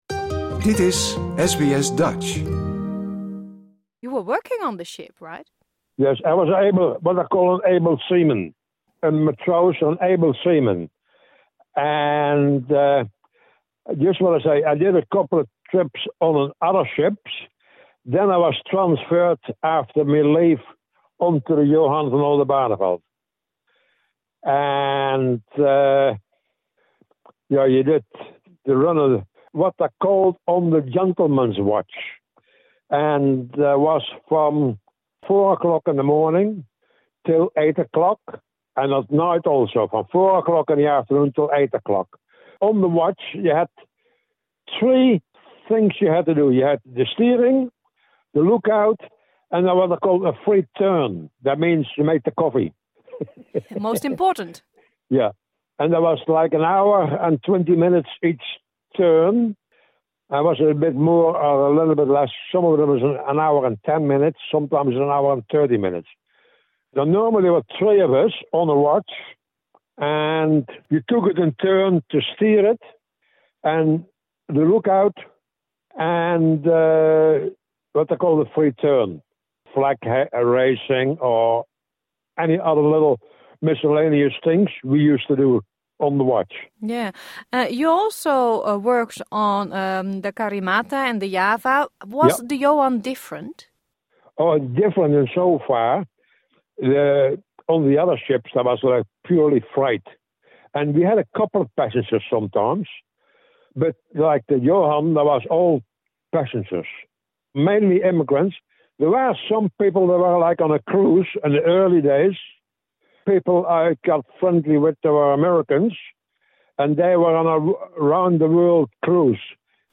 This interview is in English.